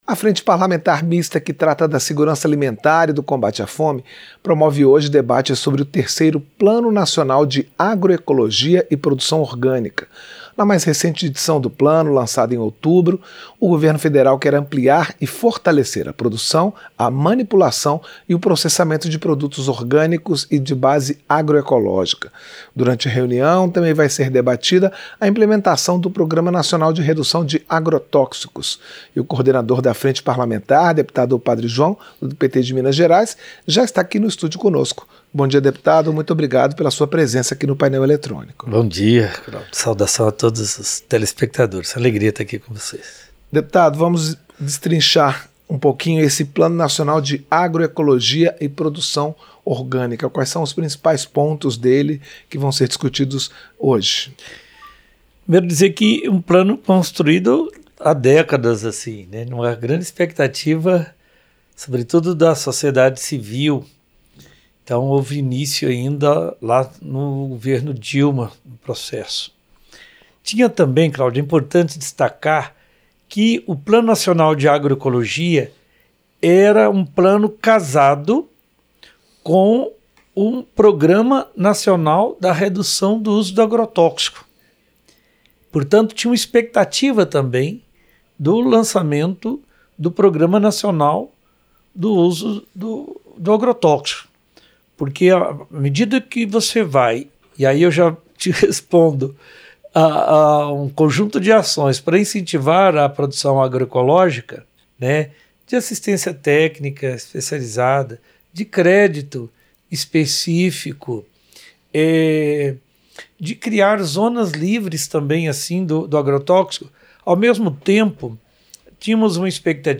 Entrevista - Dep. Padre João (PT-MG)